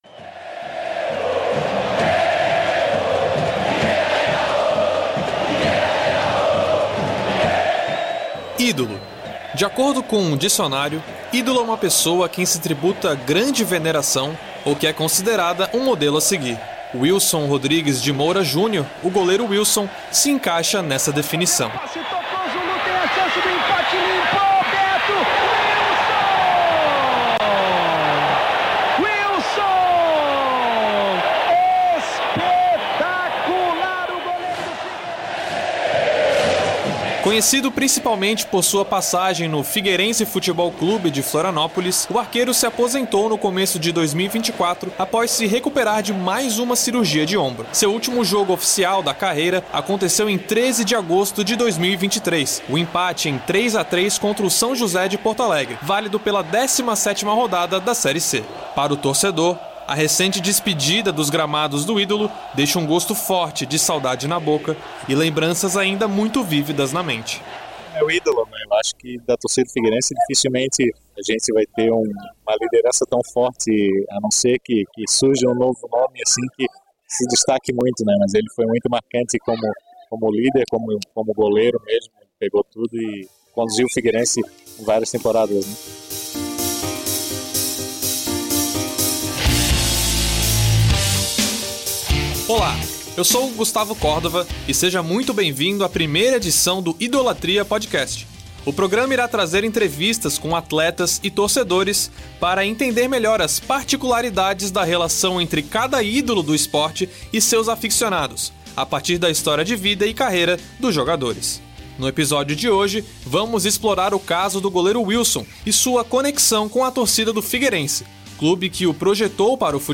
Também são utilizados efeitos sonoros e recuperação de entrevistas e narrações antigas para melhor ambientar o ouvinte e manter sua atenção e interesse no podcast.
Also, it has utilized sound effects and past interviews and narrations to better set in the listener and keep its attention and interest in the podcast.